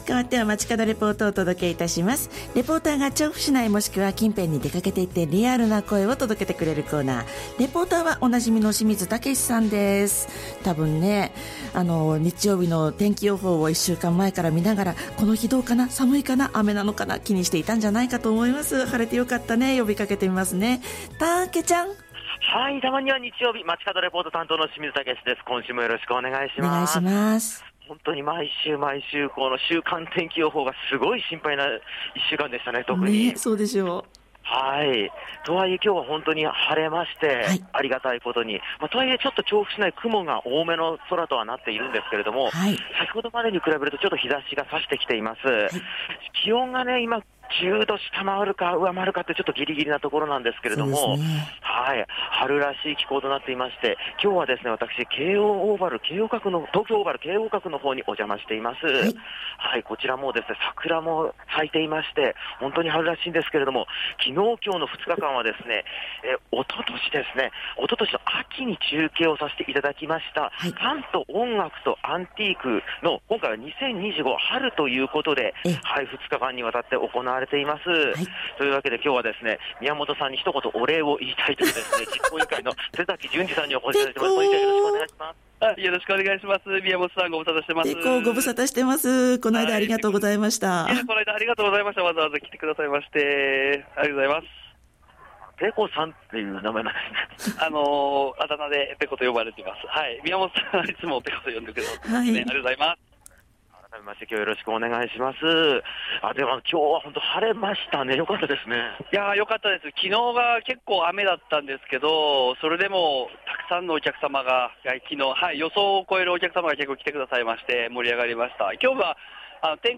★たまにち　街角レポート
京王閣にお邪魔をして 本日開催中の「パンと音楽とアンティーク2025」の会場からのレポートです！